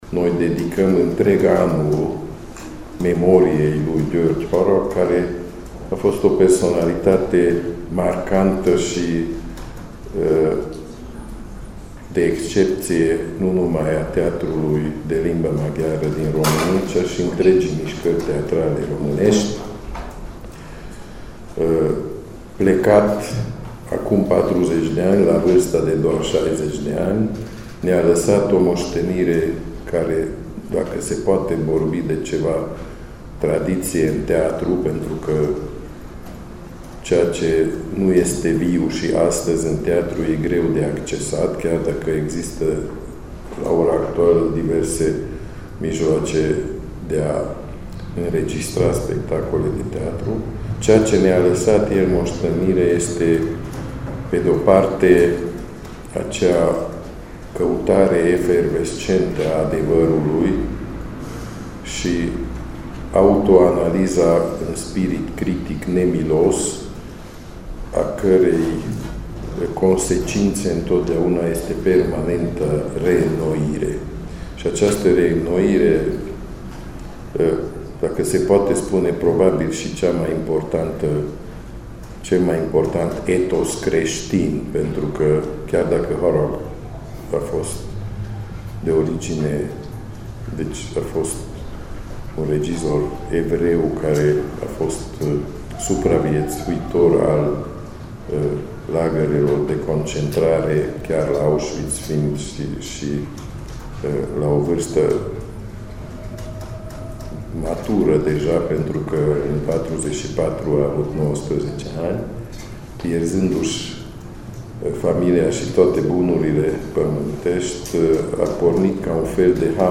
Din păcate, bugetul pentru Centenarul György Harag este foarte limitat, de aceea Teatrul Maghiar de Stat din Cluj se adresează publicului, cu rugămintea de a sprijini evenimentul, a spus la o conferință de presă directorul teatrului, regizorul Gábor Tompa.